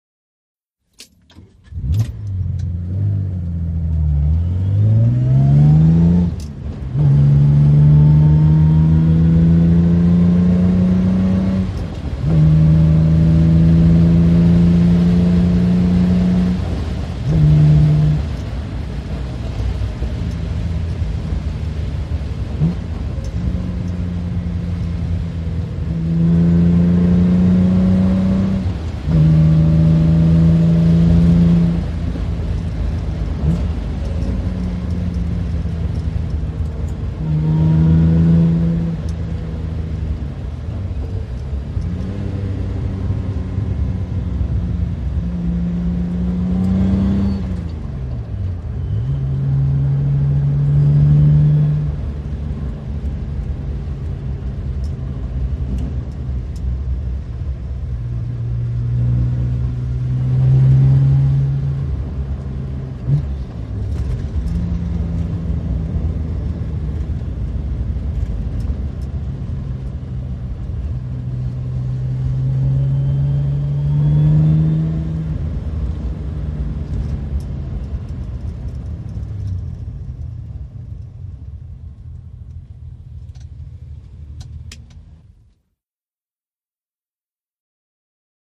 VEHICLES ASTON MARTIN: INT: Start, run with many gear changes, switch off.